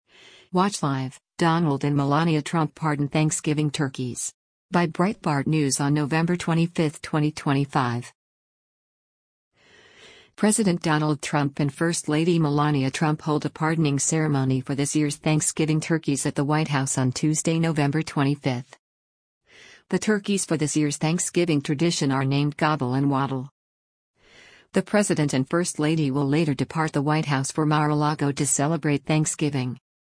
President Donald Trump and First Lady Melania Trump hold a pardoning ceremony for this year's Thanksgiving turkeys at the White House on Tuesday, November 25.